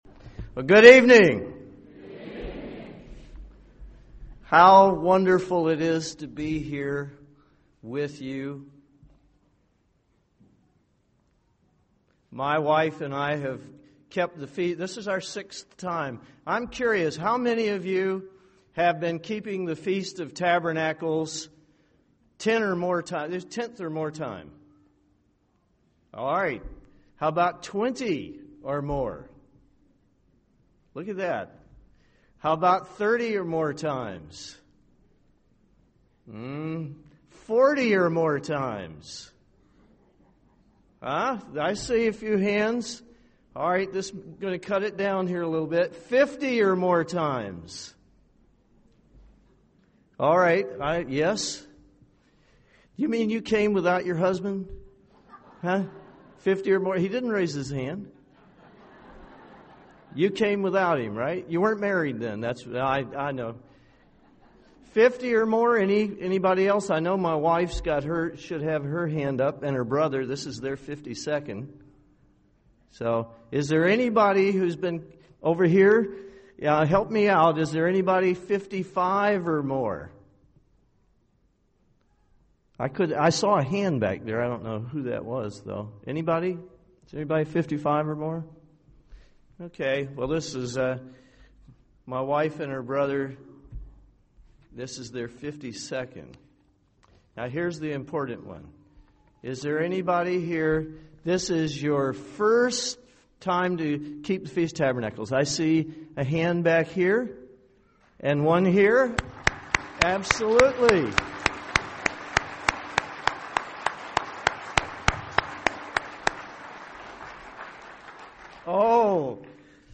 This sermon was given at the Jekyll Island, Georgia 2008 Feast site.